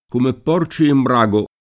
brago [ br #g o ] s. m. — in Dante, in rima, un caso di brago sicuro ( come porci in brago [ k 1 me pp 0 r © i im br #g o ]) e un caso d’incertezza, stando agli antichi manoscritti, tra brago e braco [ br # ko ]